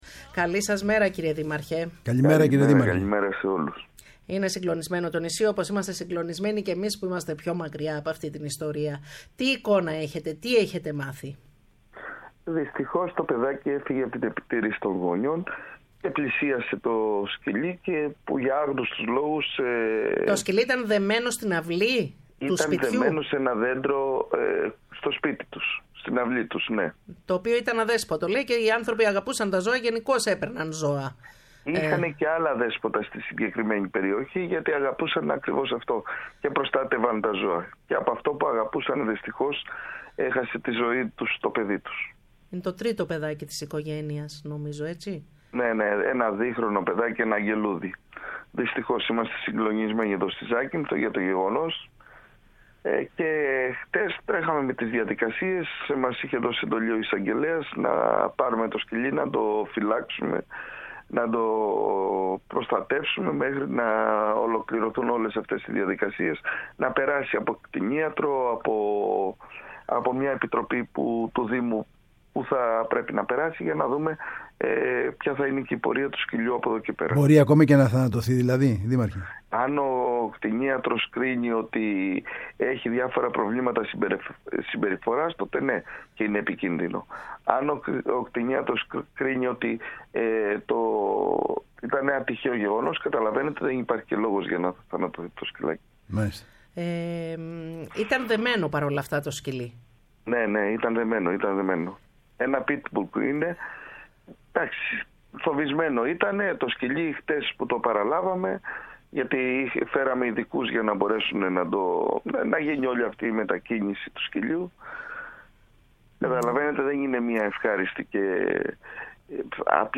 Ο Γιώργος Στασινόπουλος, Δήμαρχος Ζακύνθου, μίλησε στην εκπομπή “Ξεκίνημα”